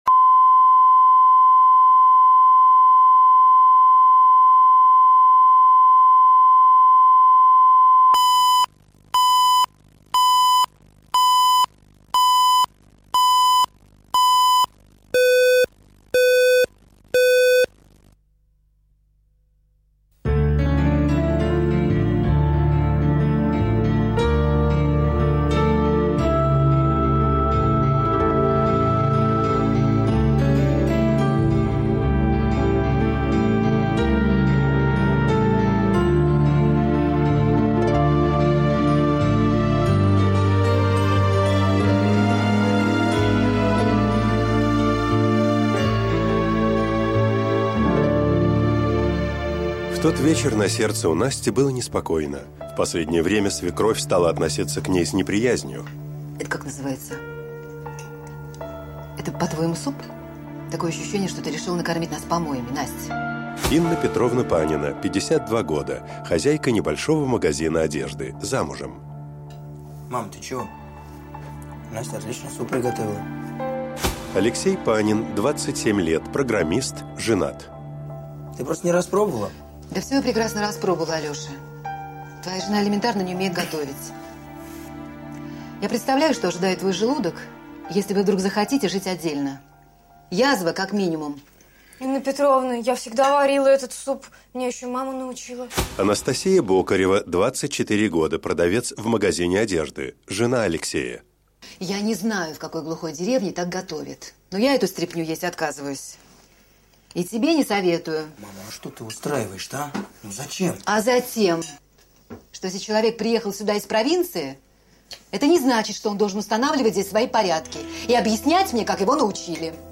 Аудиокнига Невольный обман | Библиотека аудиокниг